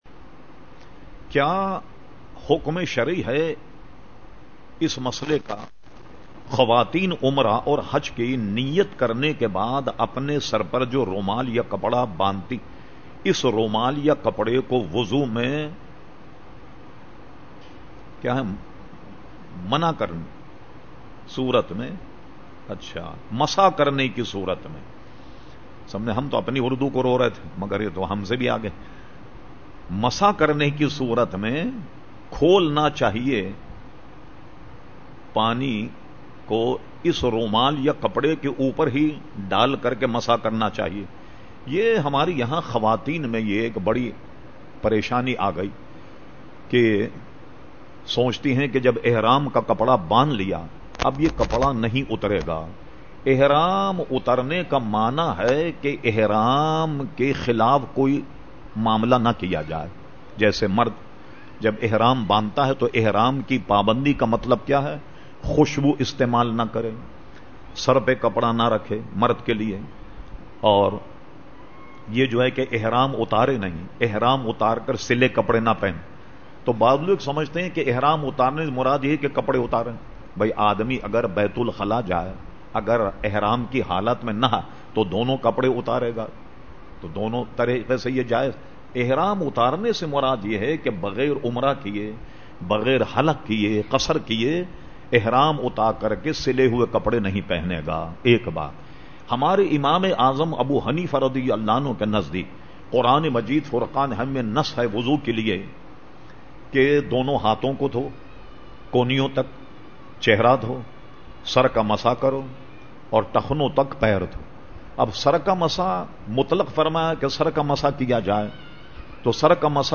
Q/A Program held on Sunday 29 August 2010 at Masjid Habib Karachi.